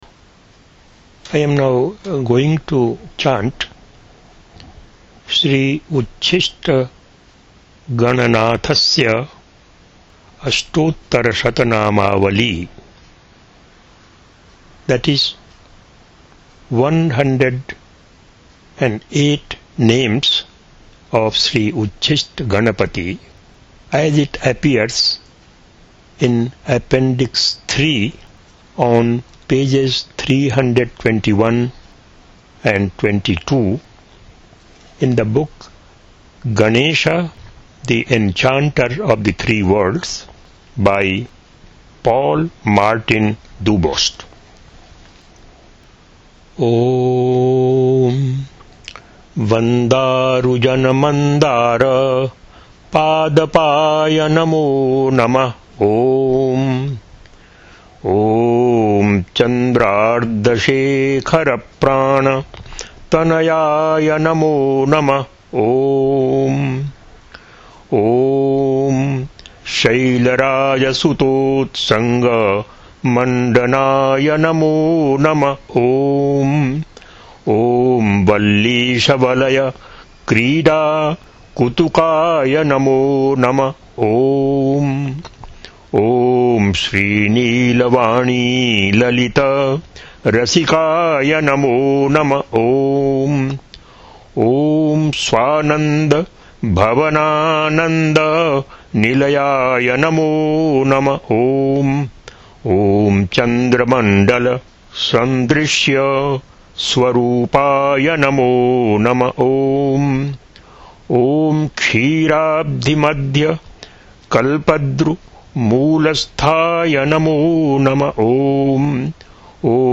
Śrī Ucchiṣṭagaṇanāthasya Aṣṭottaraśatanāmāvalī with introduction and concluding comments in English, chanted Sanskrit text (mp3, 128kbs, 20:39, 19374 KB)